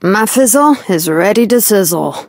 belle_start_vo_01.ogg